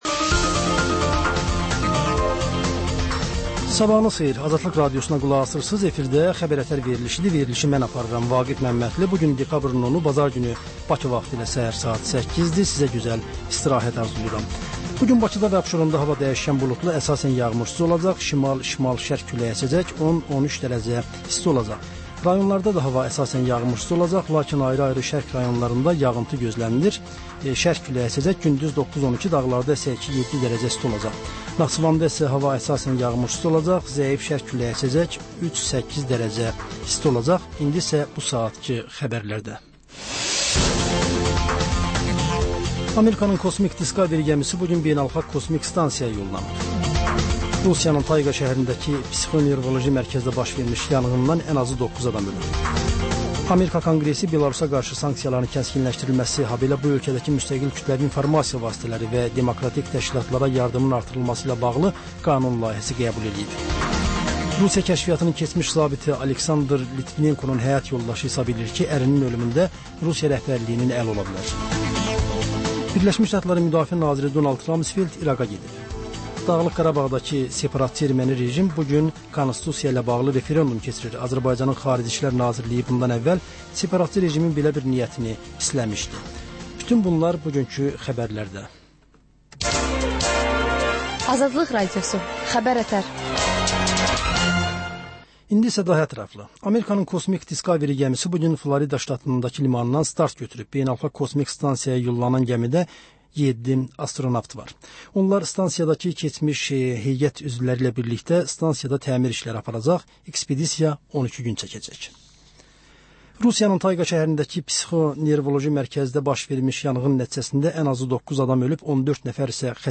Hadisələr, reportajlar. Panorama: Jurnalistlərlə həftənin xəbər adamı hadisələri müzakirə edir. Və: Qafqaz Qovşağı: Azərbaycan, Gürcüstan və Ermənistandan reportajlar.